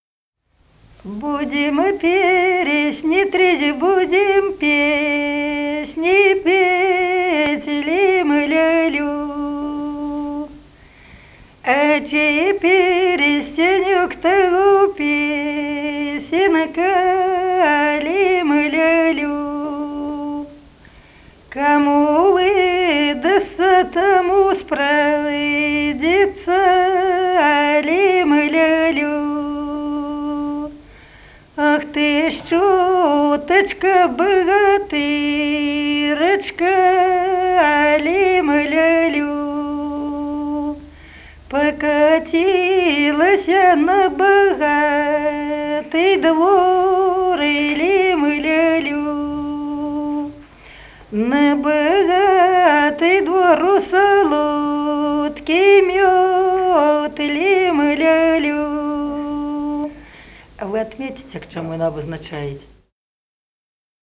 Народные песни Смоленской области
«Будем перстни тресть» (подблюдные).